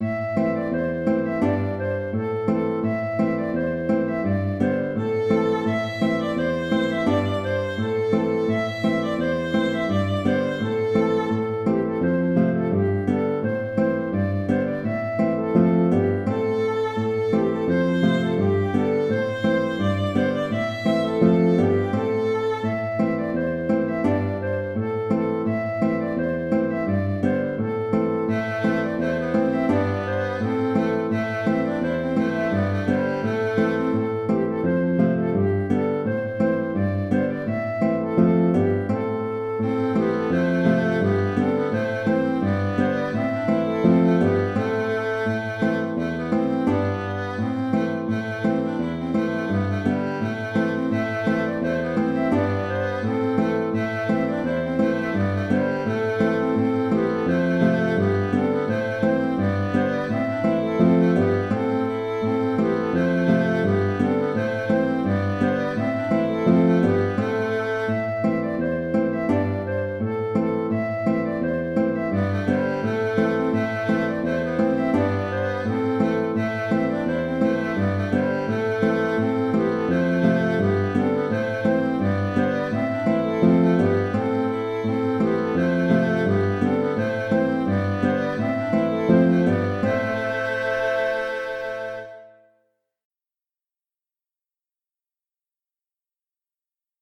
An dro d'Alan (An dro) - Musique bretonne